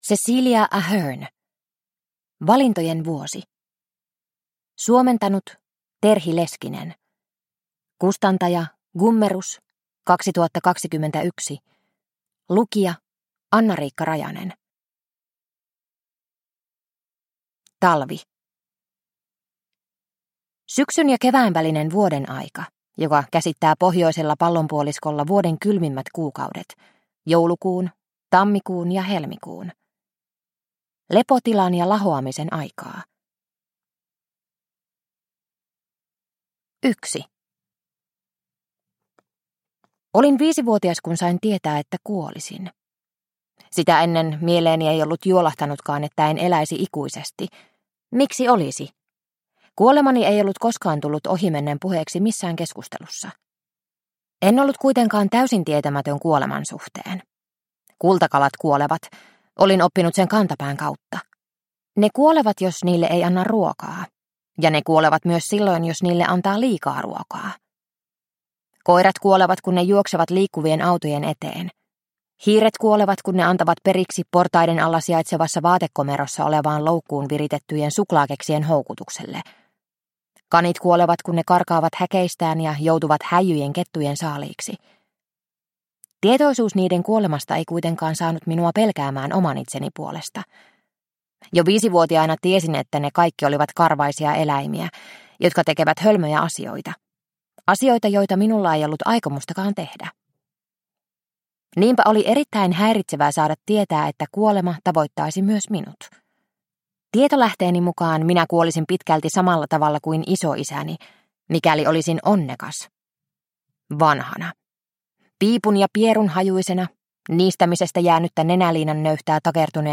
Valintojen vuosi – Ljudbok – Laddas ner